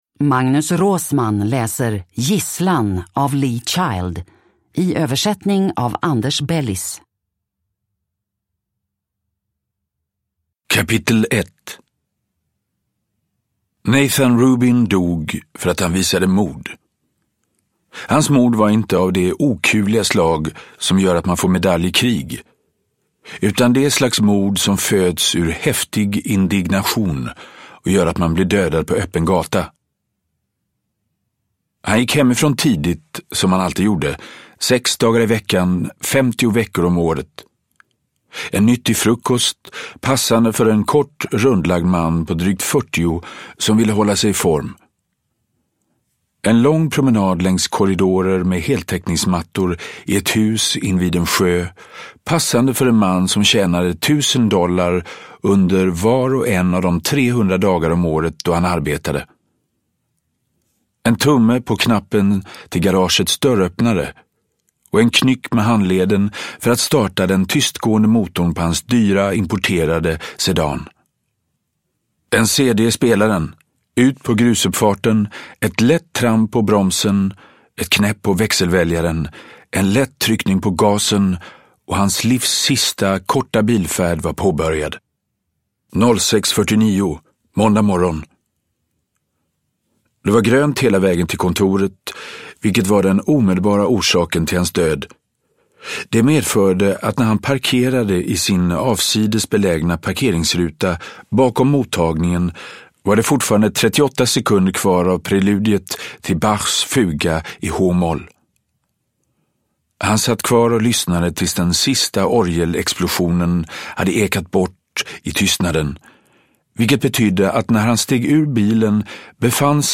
Gisslan – Ljudbok
Deckare & spänning Njut av en bra bok
Uppläsare: Magnus Roosmann